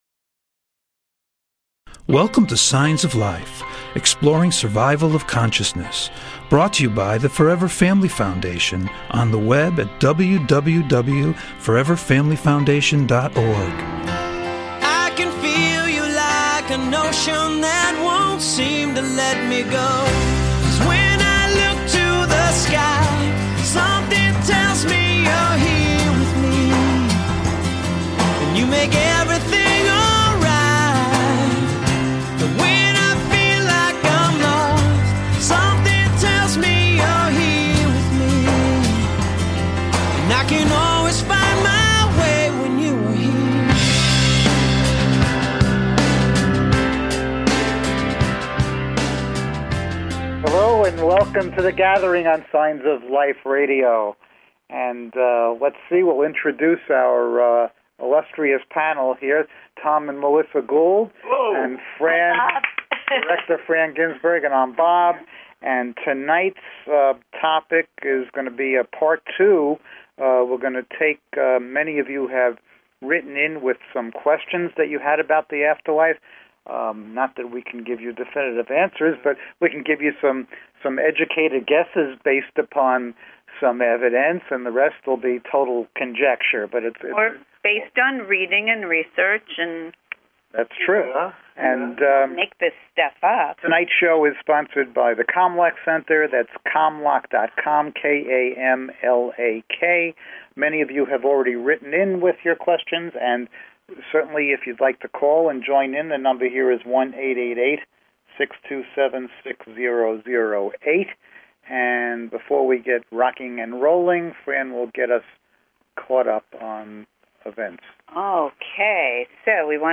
Signs of Life Radio Show is a unique radio show dedicated to the exploration of Life After Death!